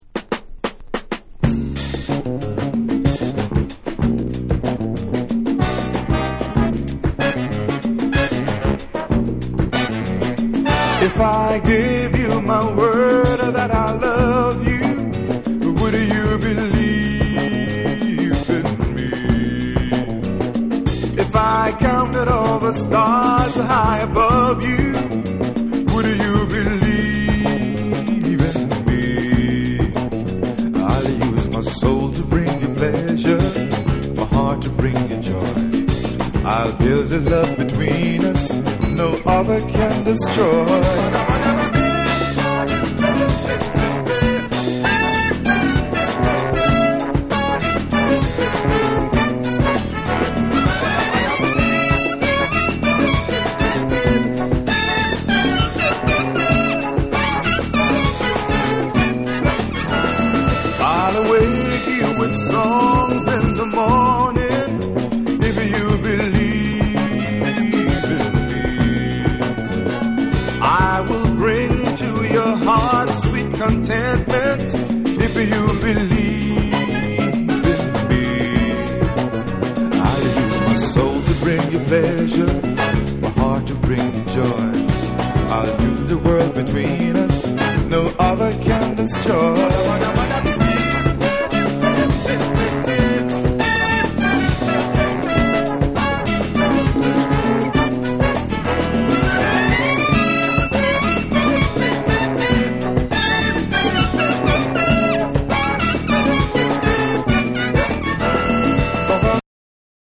スキャットとホーンのユニゾンが高揚感抜群のグルーヴィーチューン
レア・グルーヴ〜ジャズ・ファンク
SOUL & FUNK & JAZZ & etc / BREAKBEATS